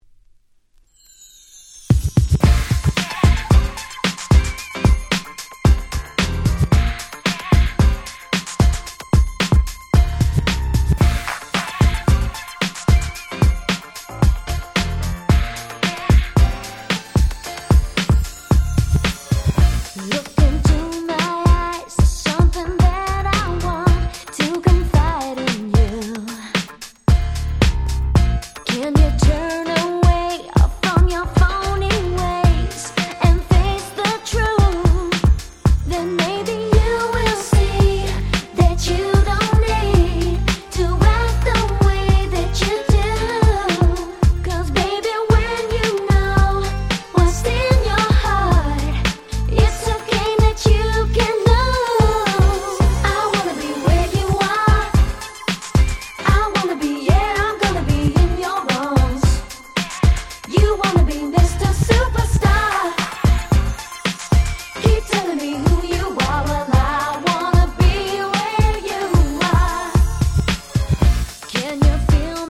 01' Very Nice UK R&B !!
キャッチー系